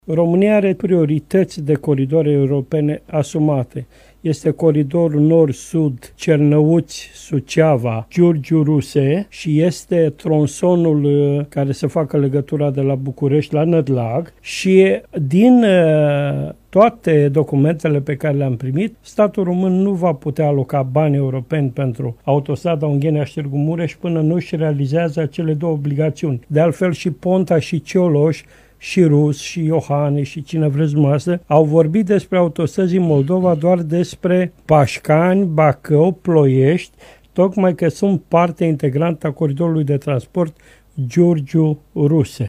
Statul roman nu poate aloca fonduri europene pentru alte trasee de autostrada, înaintea celor enunțate, a declarat deputatul PMP de Iași, Petru Movilă, în emisiunea Imperatv, la Radio Iași: